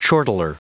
Prononciation du mot chortler en anglais (fichier audio)
Prononciation du mot : chortler